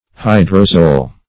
hydrozoal - definition of hydrozoal - synonyms, pronunciation, spelling from Free Dictionary Search Result for " hydrozoal" : The Collaborative International Dictionary of English v.0.48: Hydrozoal \Hy`dro*zo"al\, a. (Zool.) Of or pertaining to the Hydrozoa.
hydrozoal.mp3